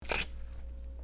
squish.mp3